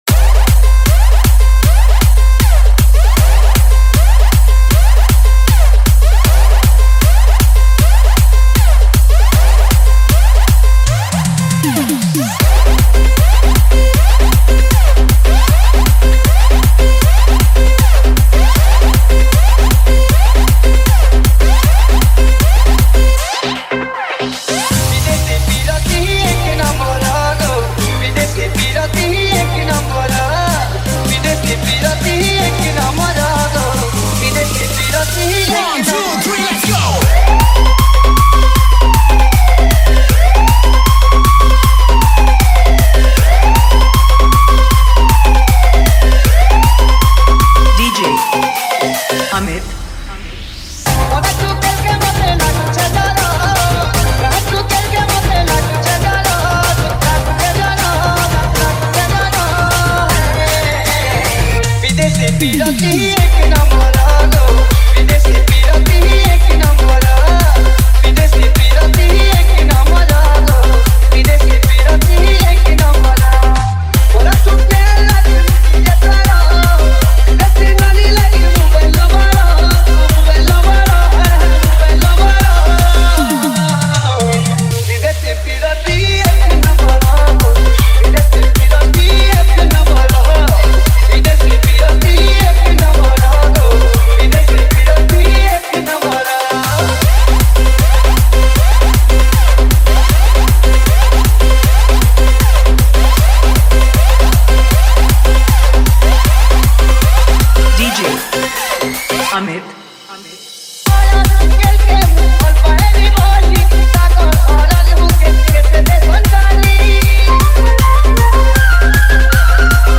Sambalpuri Dj Song 2024
Category:  Old Sambalpuri Dj Song